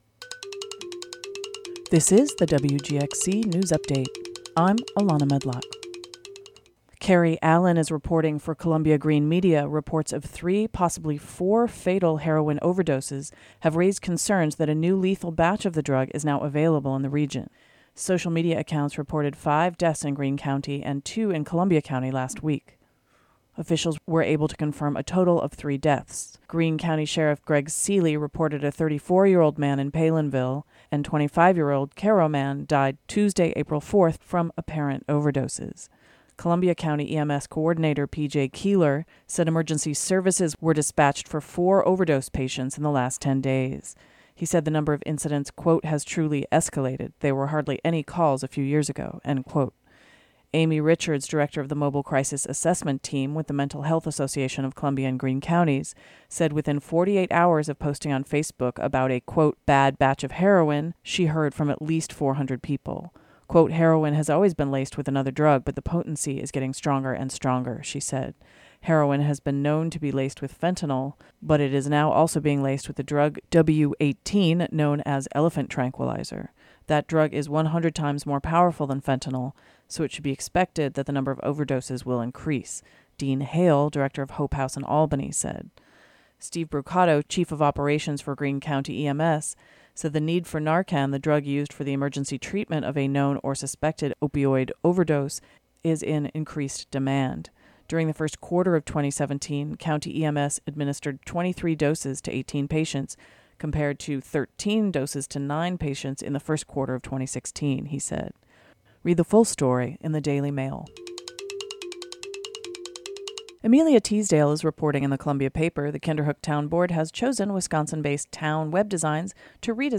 Click here to listen to WGXC's Congressional report, a look back at the week in news for Rep. John Faso (R-Kinderhook).